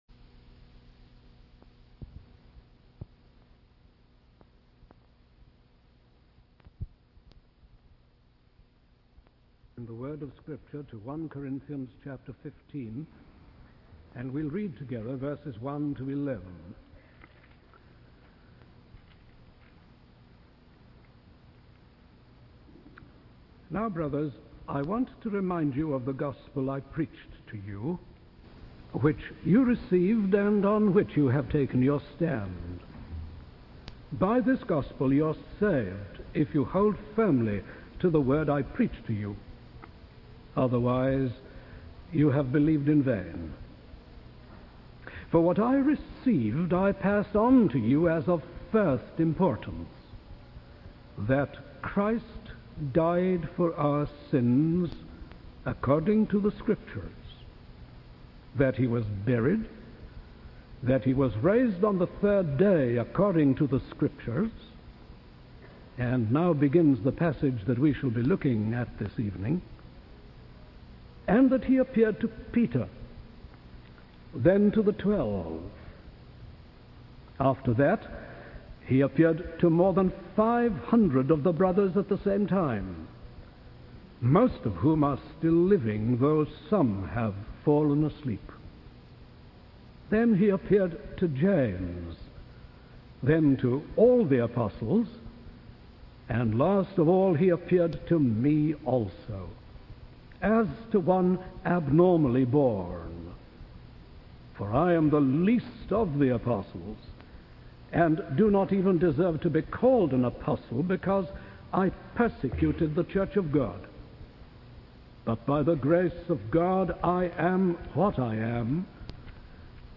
In this sermon, the speaker emphasizes the importance of having a living mediator, Jesus Christ, who brings us into communion with God. The Apostle Paul is highlighted as a witness to the resurrection of Jesus. The speaker explains that Paul's experience of seeing the risen Lord was not a subjective vision, but an objective reality.